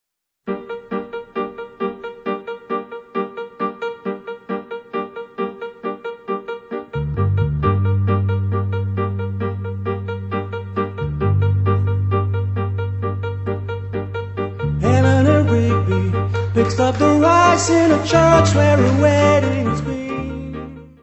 piano
baixo
bateria.
Music Category/Genre:  Pop / Rock